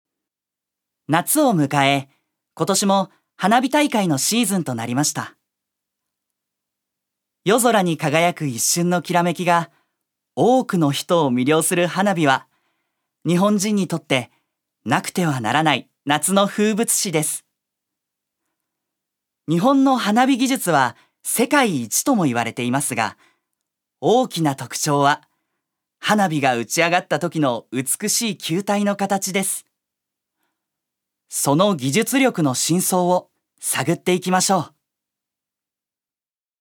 所属：男性タレント
ナレーション２